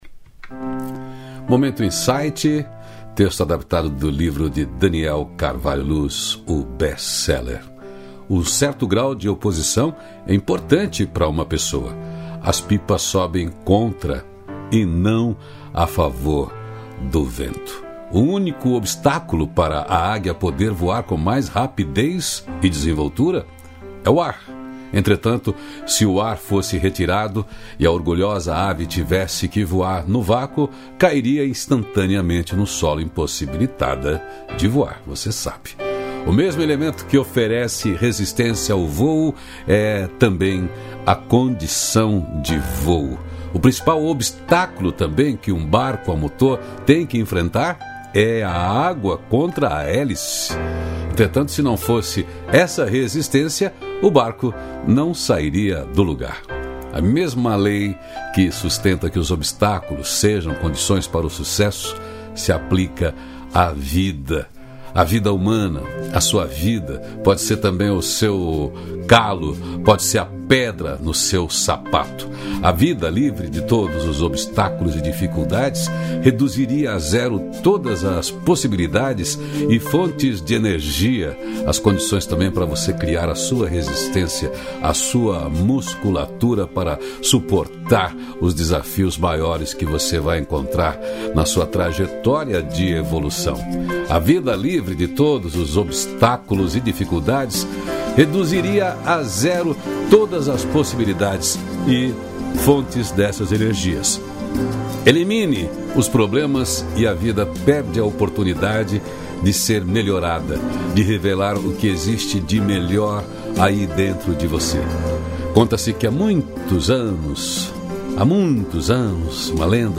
Música: Não há pedras no caminho… Banda Aquática Álbum: Nova Manhã